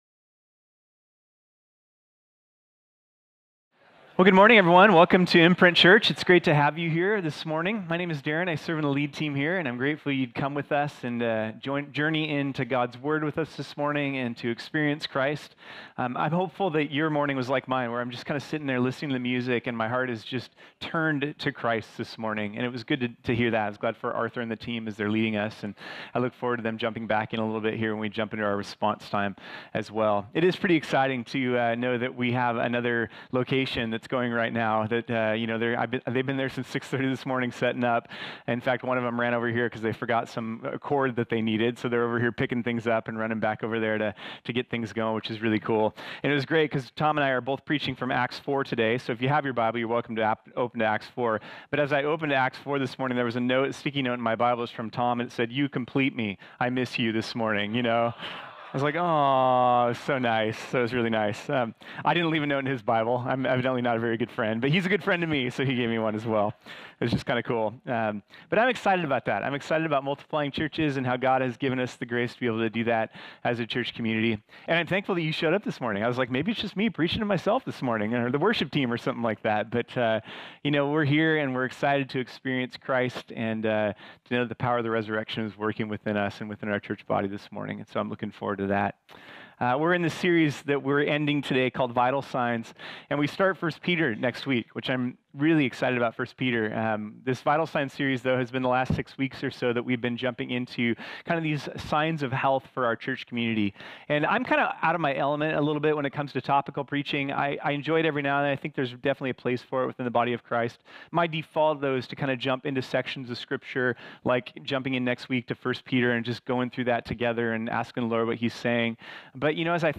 This sermon was originally preached on Sunday, February 20, 2022.